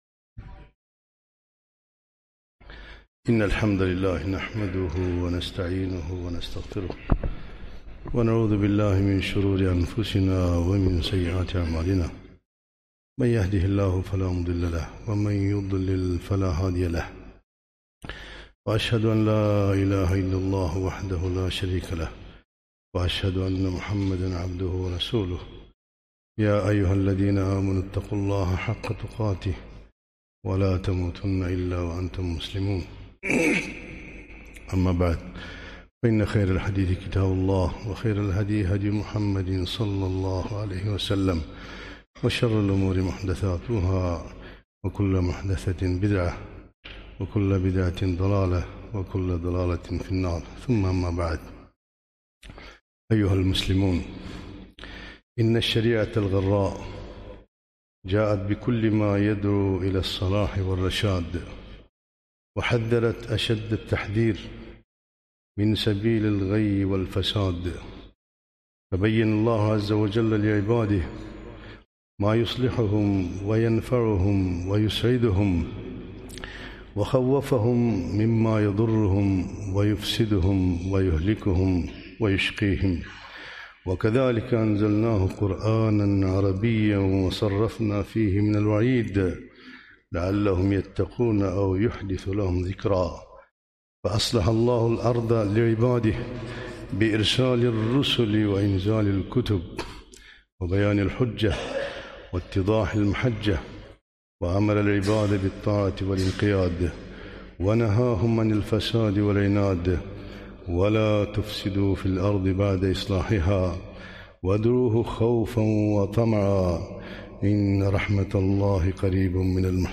خطبة - ولا تفسدوا في الأرض بعد إصلاحها